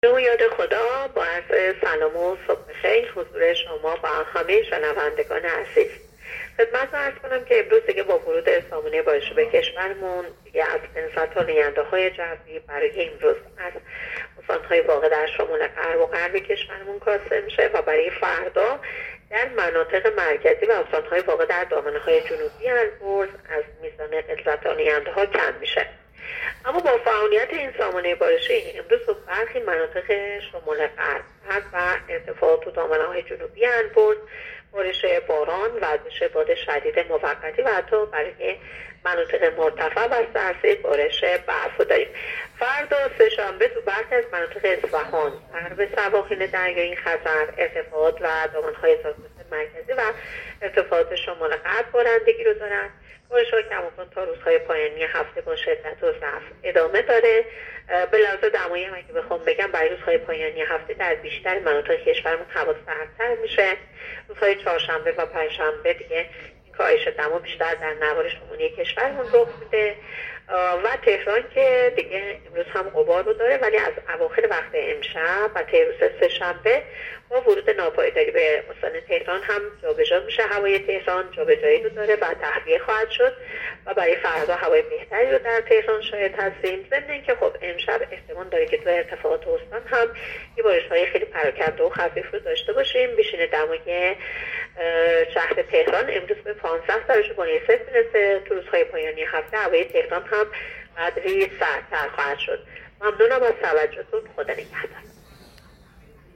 گزارش رادیو اینترنتی پایگاه‌ خبری از آخرین وضعیت آب‌وهوای ۱۰ آذر؛